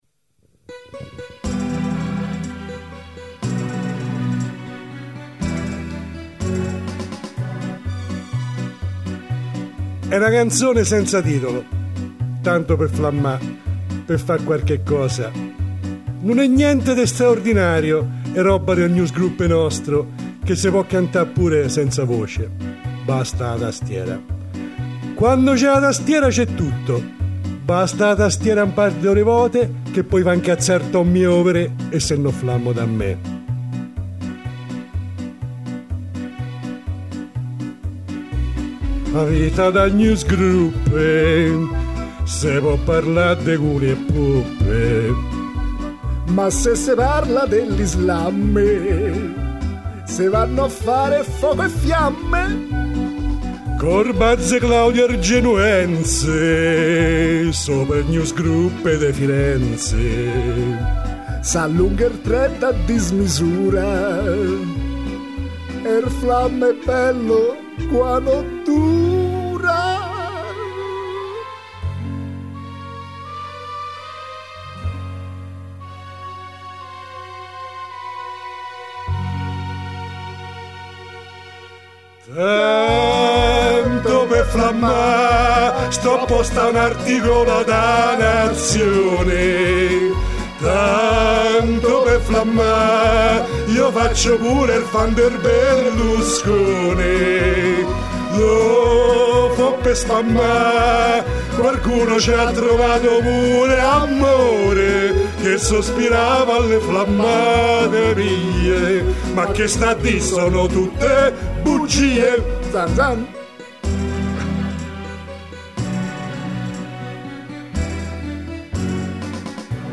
cantata in un improbabile romanesco